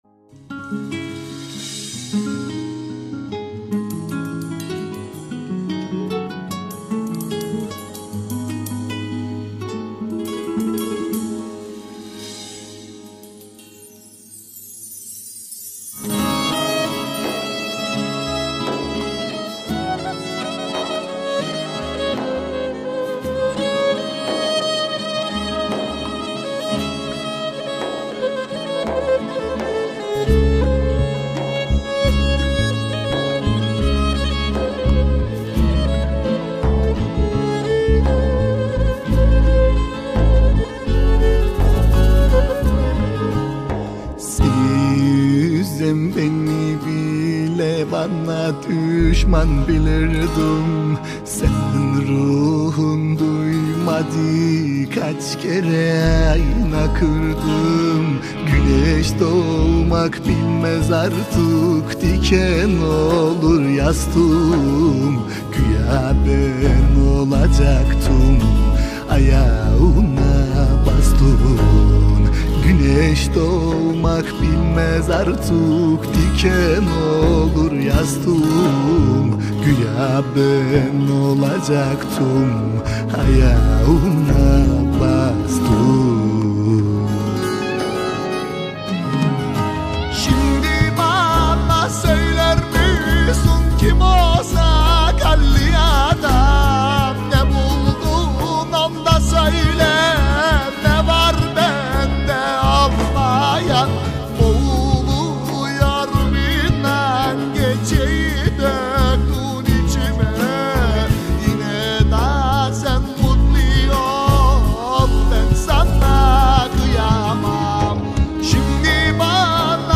دانلود آهنگ غمگین استانبولی – ۳